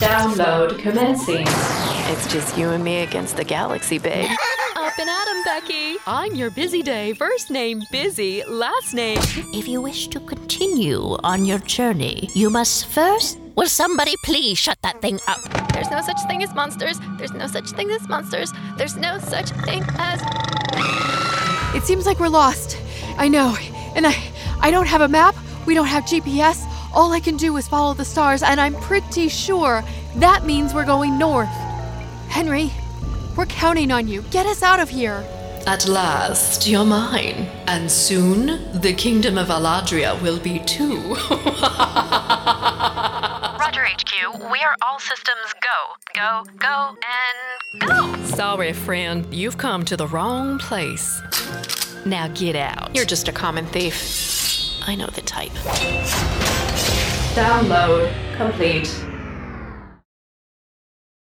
Animation & Video Game Voiceover